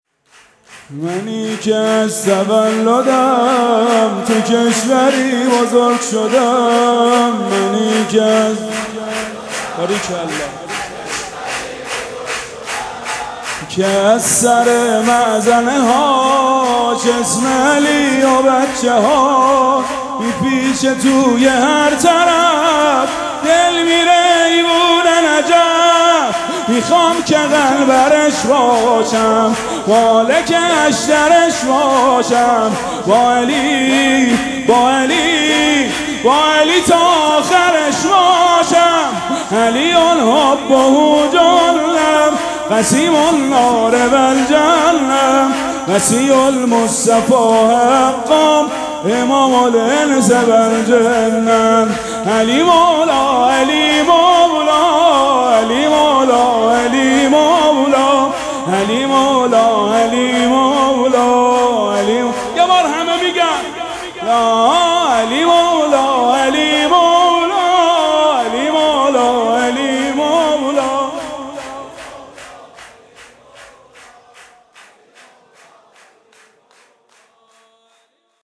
مراسم جشن ولادت امیرالمومنین(ع)
شور
مداح